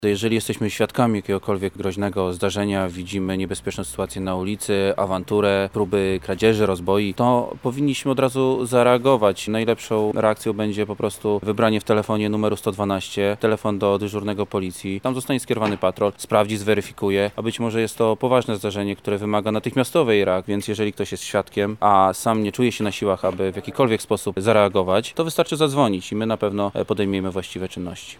Funkcjonariusz przypomina o czynnościach, jakie należy podjąć w przypadku groźnej sytuacji.